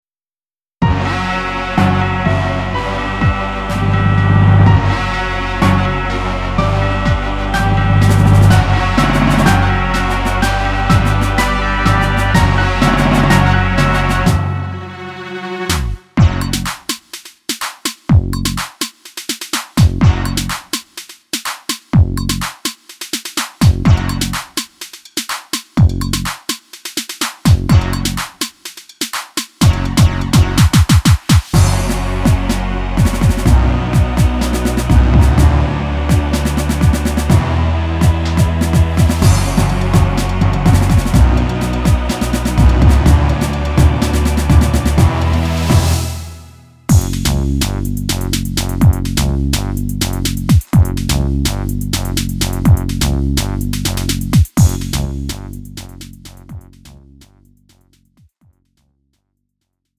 음정 -1키 2:55
장르 가요 구분 Lite MR